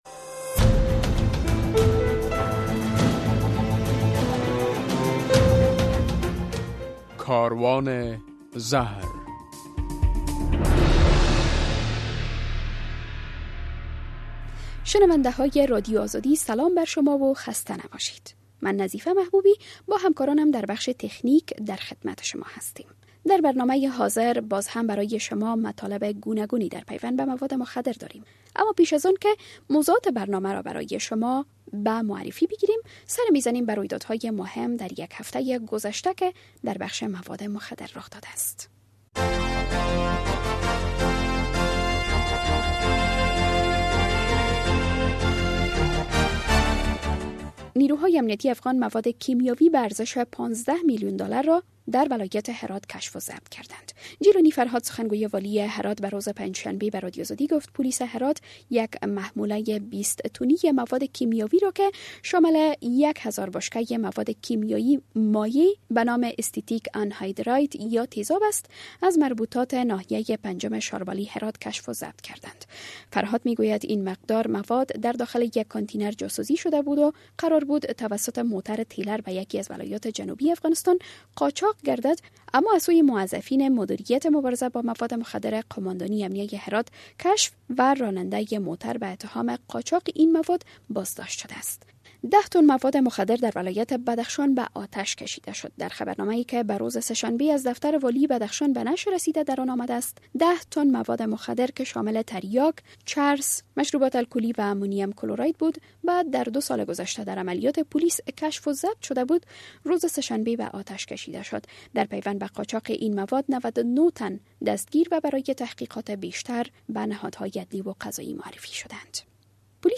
در برنامه این هفته کاروان زهر، در نخست خبرها، بعداً گزارش‌ها، بعد از آن مصاحبه و به تعقیب آن خاطره یک معتاد و ...